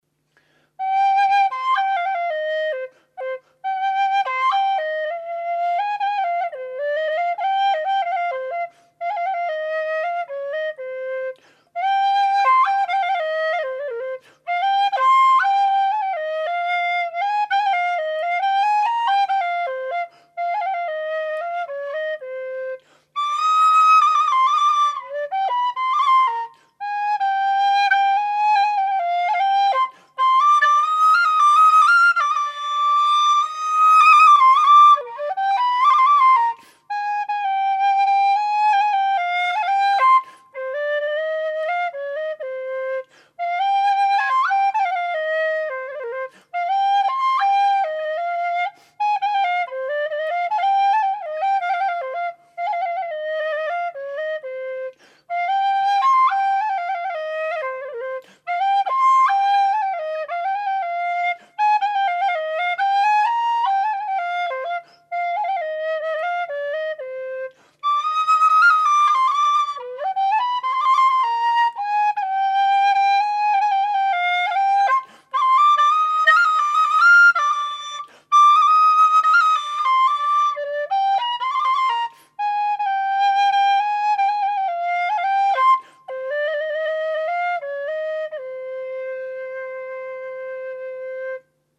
Bb whistle - 145 GBP
made out of thin-walled aluminium tubing with 15.7mm bore